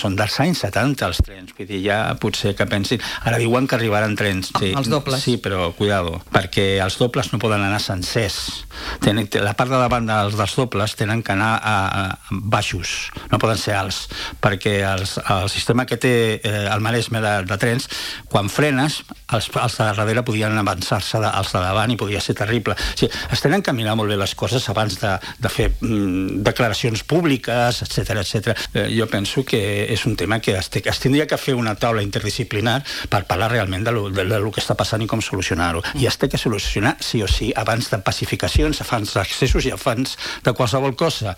Aquest dilluns, a l’entrevista del matinal de RCT